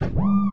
robostep.ogg